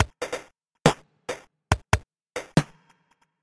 707 Dubstep鼓
描述：ubstep鼓与707踢脚、小鼓和手鼓。
Tag: 140 bpm Dubstep Loops Drum Loops 590.67 KB wav Key : Unknown